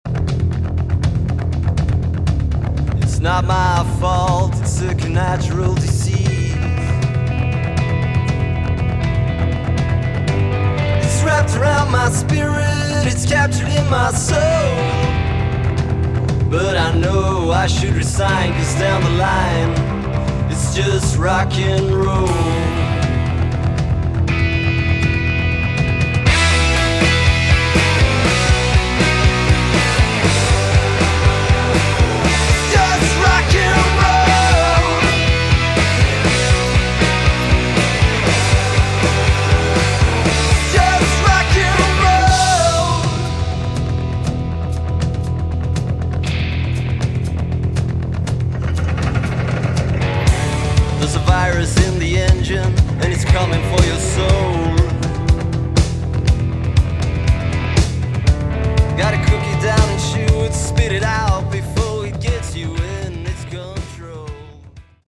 Category: Hard Rock
guitar, vocals
drums